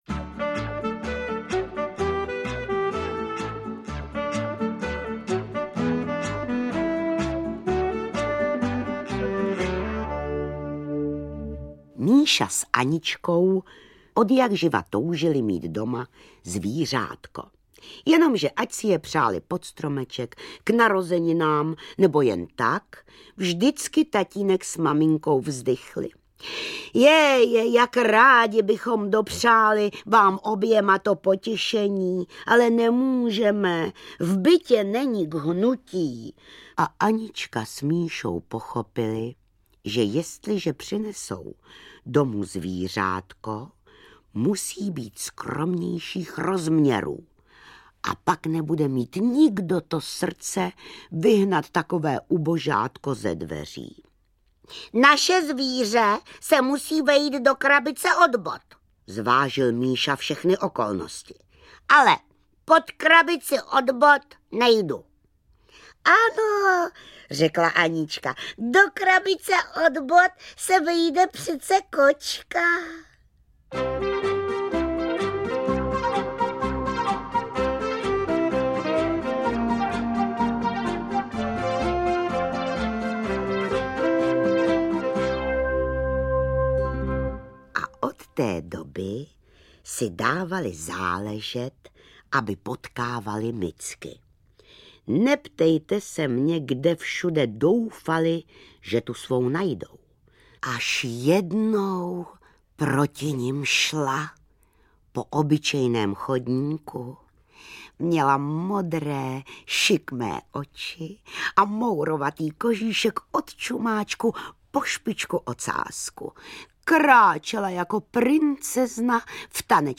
Interpreti:  Jiřina Bohdalová, Antonín Jedlička